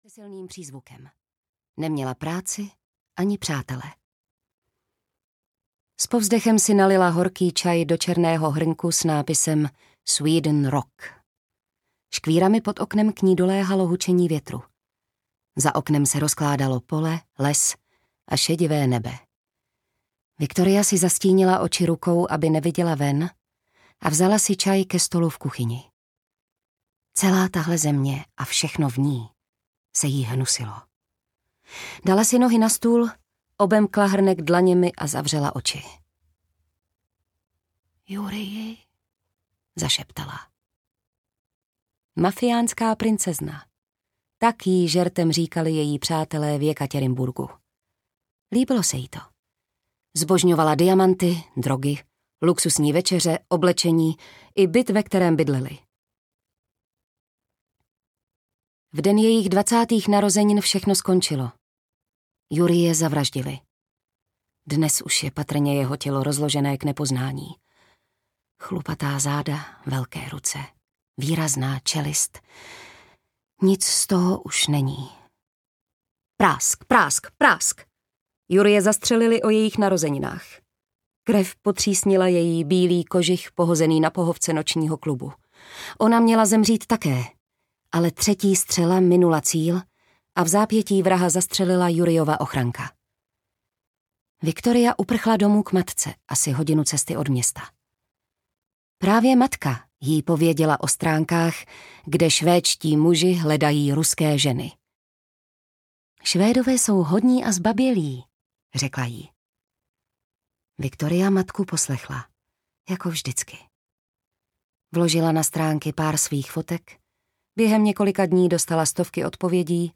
Ženy bez slitování audiokniha
Ukázka z knihy
zeny-bez-slitovani-audiokniha